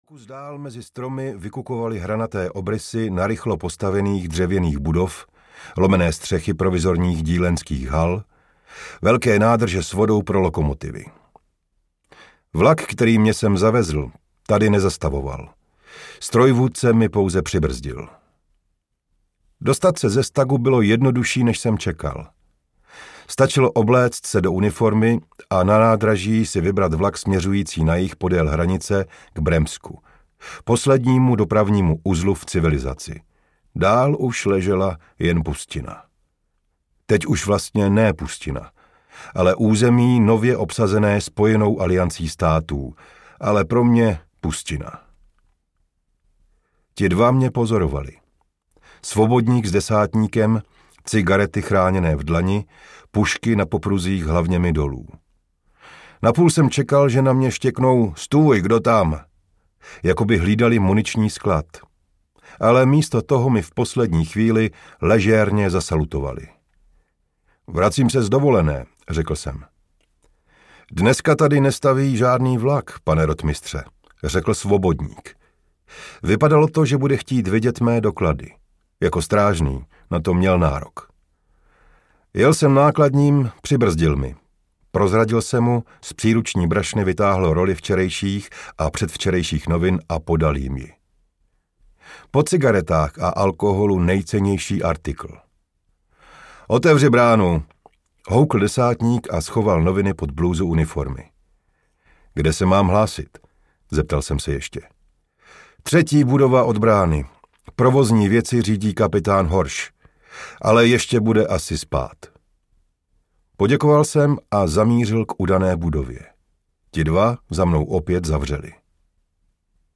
Kapitán audiokniha
Ukázka z knihy